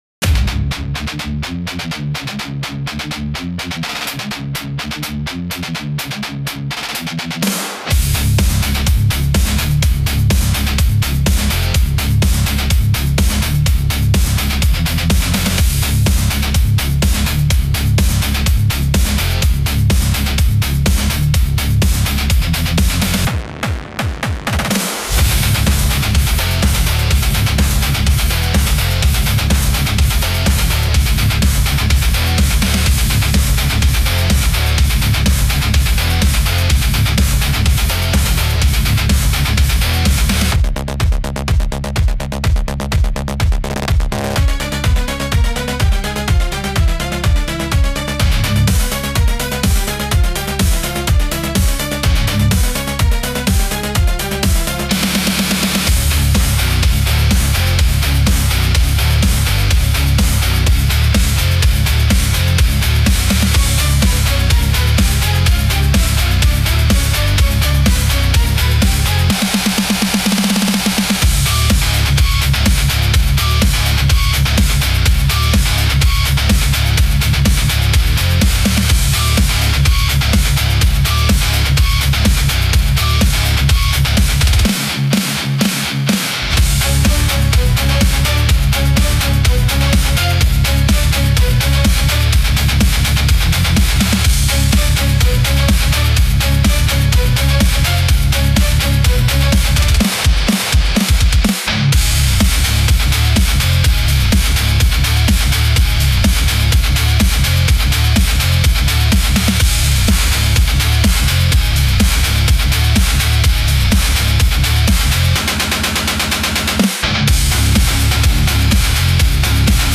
Style: Industrial Rock/Metal) (Instrumental Theme